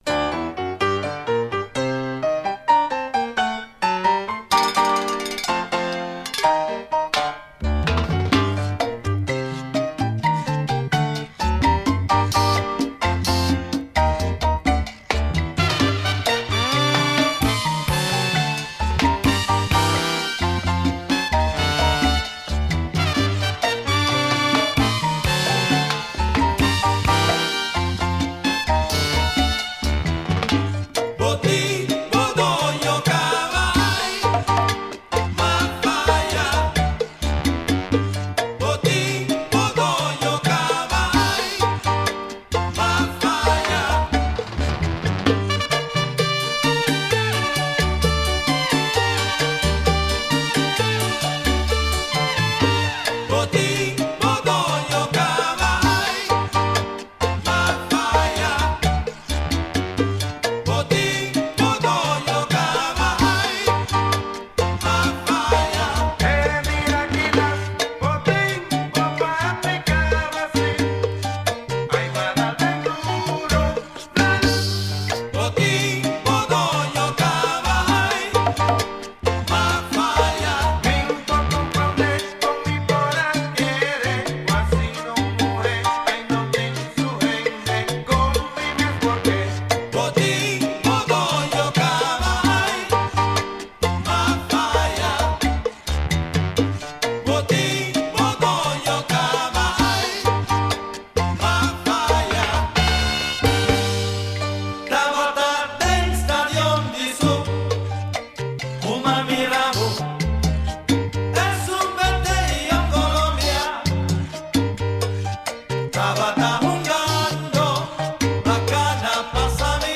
Karakterístikanan di un Kantika-Kuenta: